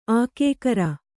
♪ ākēkara